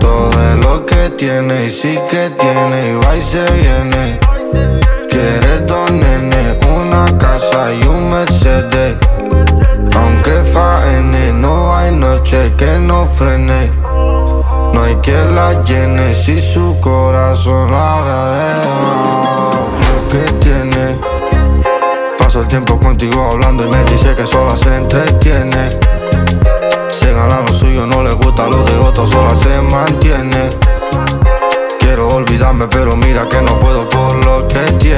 Жанр: Африканская музыка
# Afro-Beat